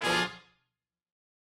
GS_HornStab-Bdim.wav